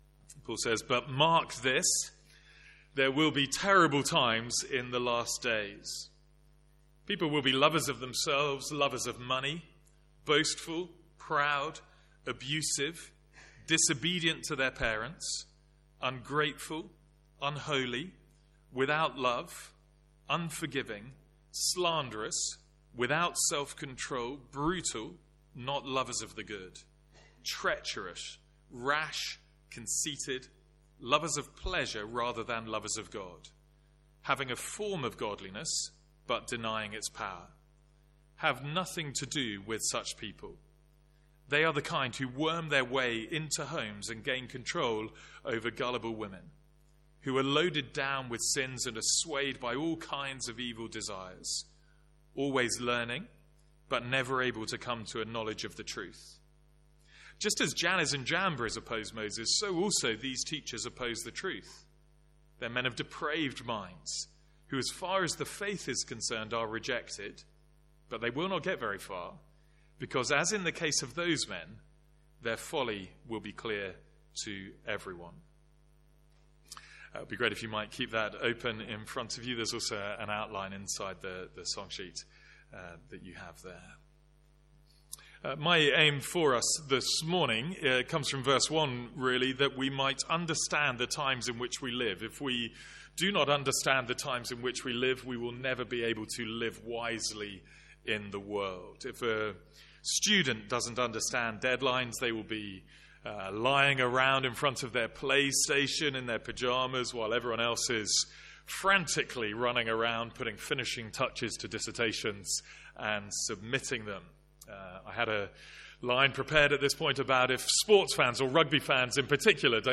Sermons | St Andrews Free Church
From our morning series in 2 Timothy.